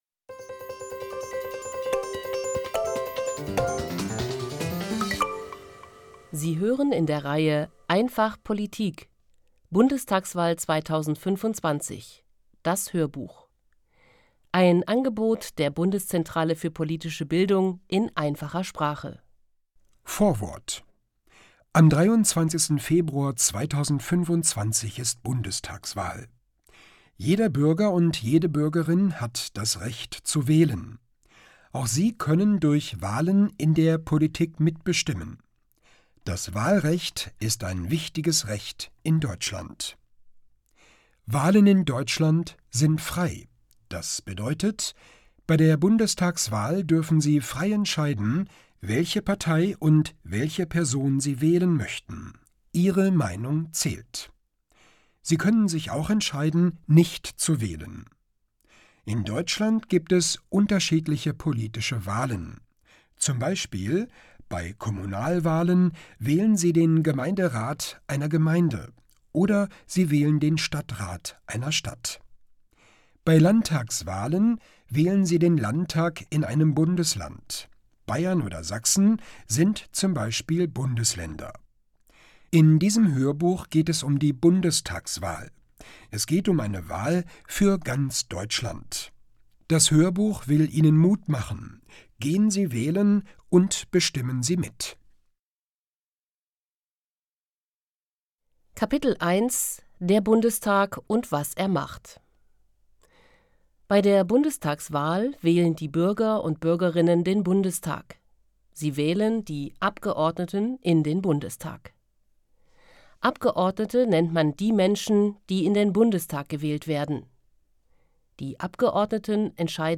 einfach POLITIK: Bundestagswahl 2025. Das Hörbuch Hörbuch in einfacher Sprache
Produktion: Studio Hannover